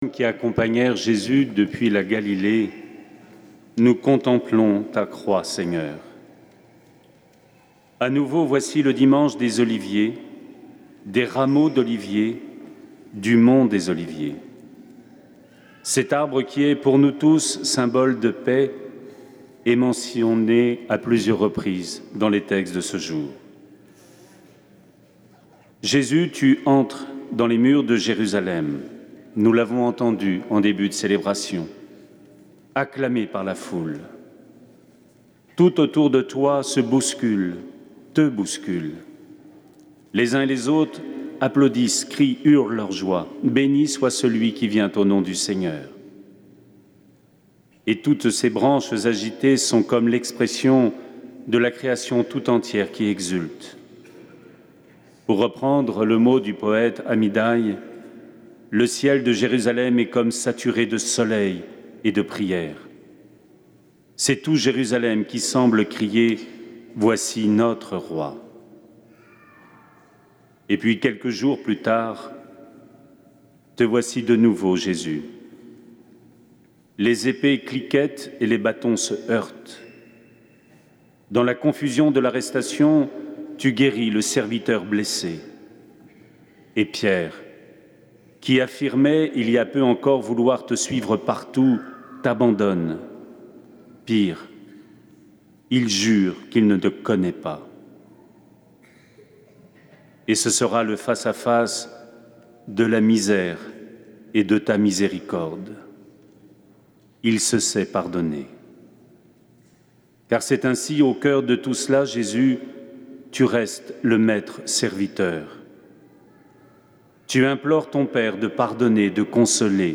Homélie
Jour de Pâques